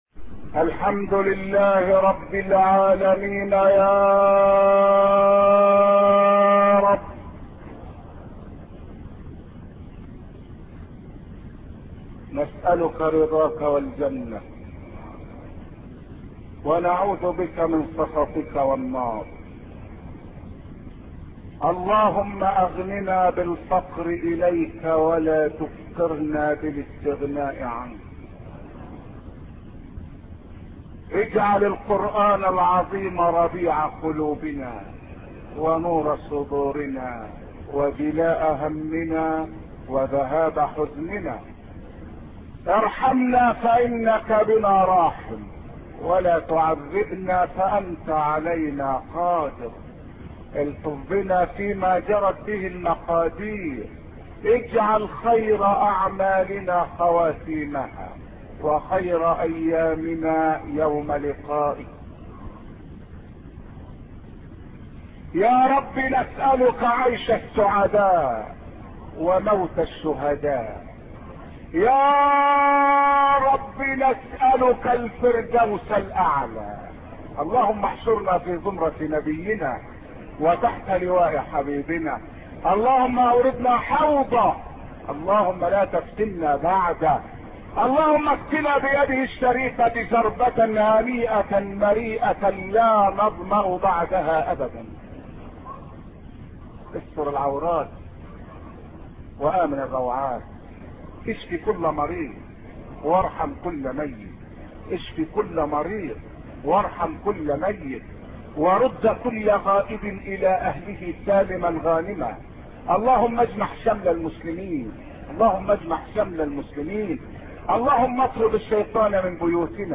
أرشيف الإسلام - ~ أرشيف صوتي لدروس وخطب ومحاضرات الشيخ عبد الحميد كشك